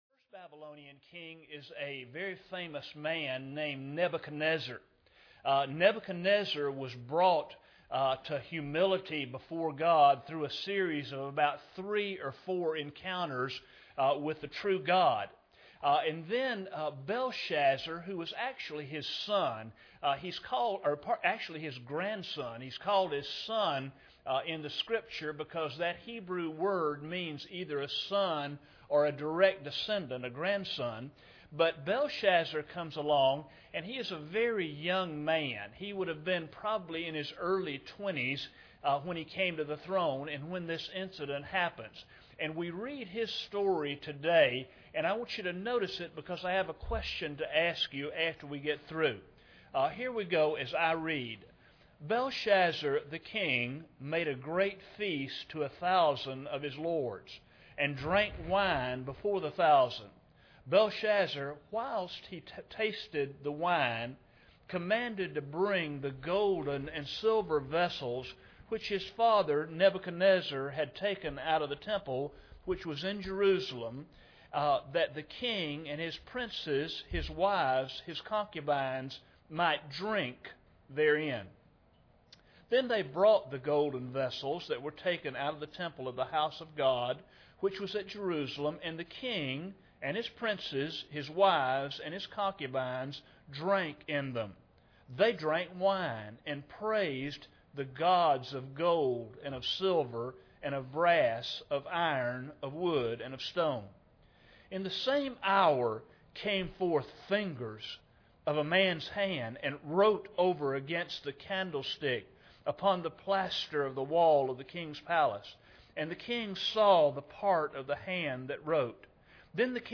Daniel 5:1-31 Service Type: Sunday Morning Bible Text